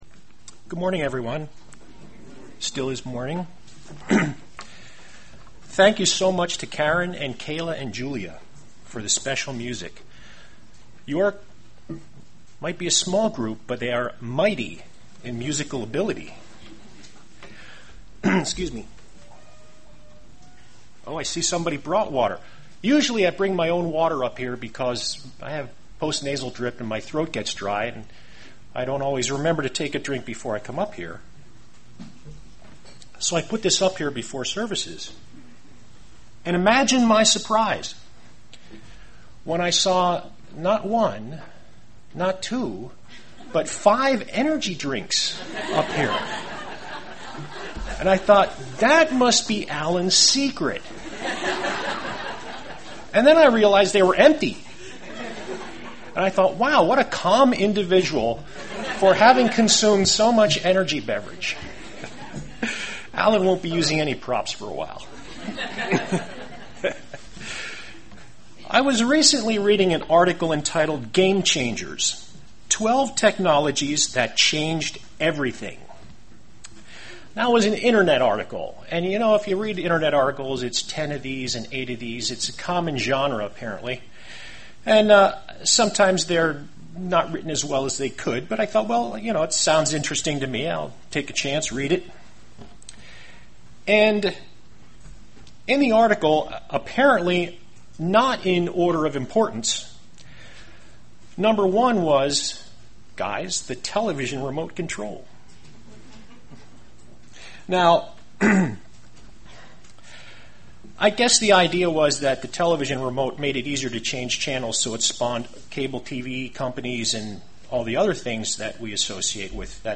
Given in Lehigh Valley, PA
UCG Sermon Studying the bible?